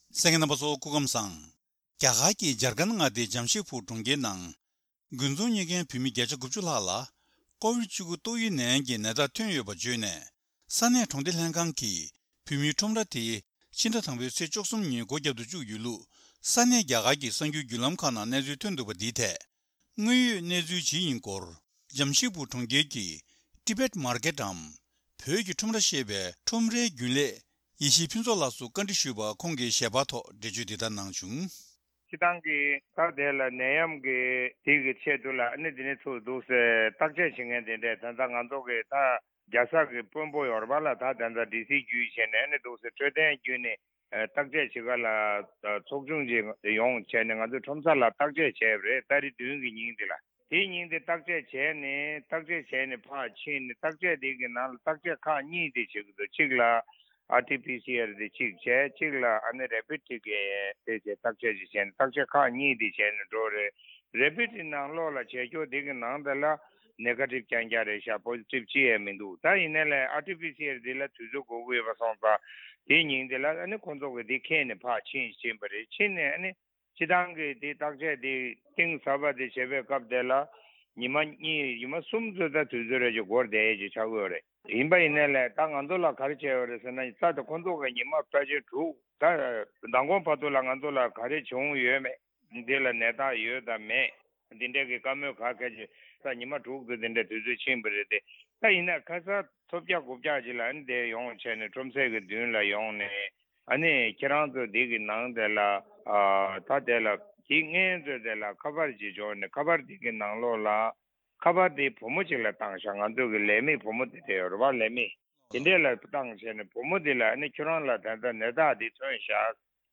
བཀའ་འདྲི་ཞུས་པར་གསན་རོགས་ཞུ།